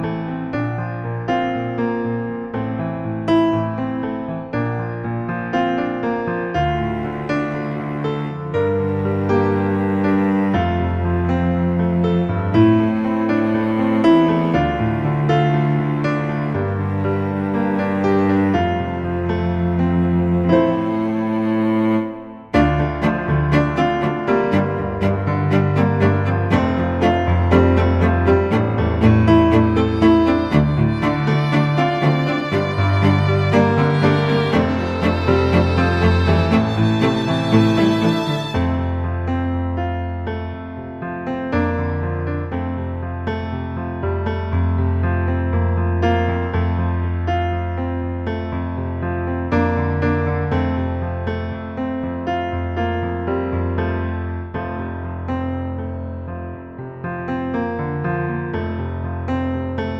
Down 3 Semitones For Male